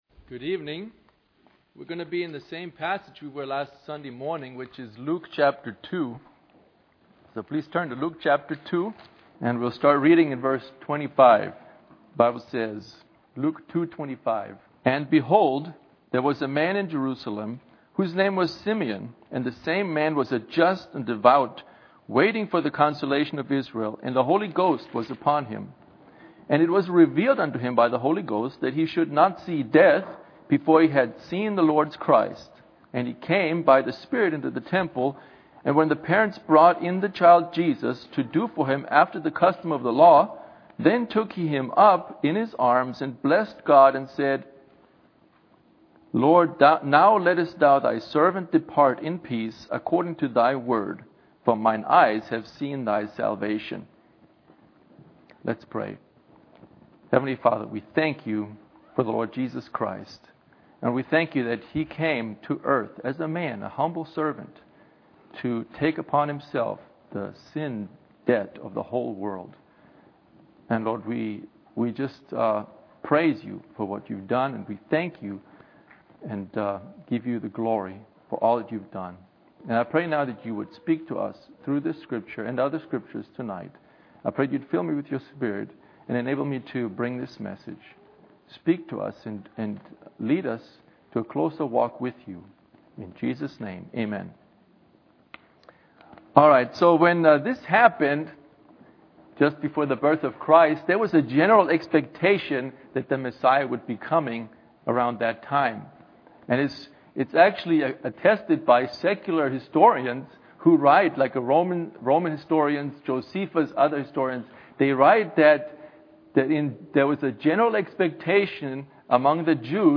Passage: Luke 2:25-34 Service Type: Wednesday Evening